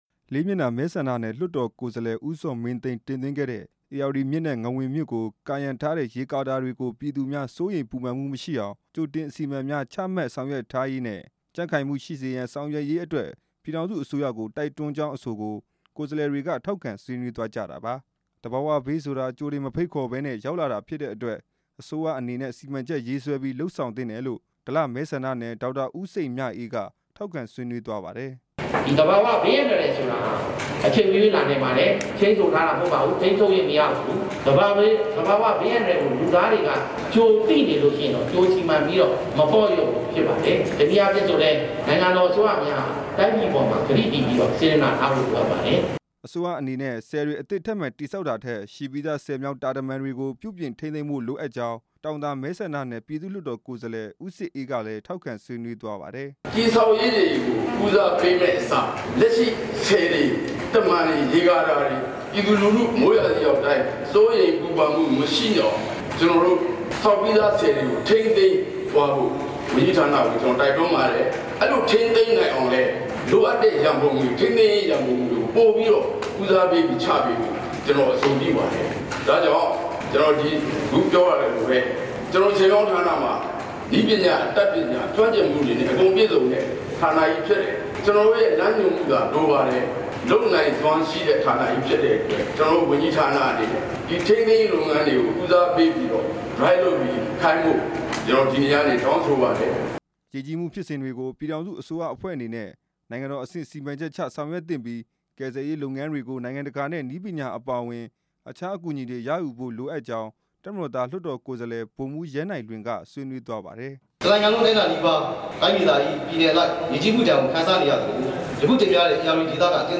လွန်ခဲ့တဲ့ ၁၉၇၄ ၊ ၁၉၈၄ နဲ့ ၂၀၀၄ ခုနှစ်တွေက ငဝန်ရေကာတာနိမ့်ကျခဲ့တဲ့ အစဉ်အလာတွေရှိခဲ့တယ် လို့ ရေကြည်မဲဆန္ဒနယ်က ပြည်သူ့လွှတ်တော်ကိုယ်စားလှယ် ဒေါက်တာစံရွှေဝင်း က ဆွေးနွေးခဲ့ပါတယ်။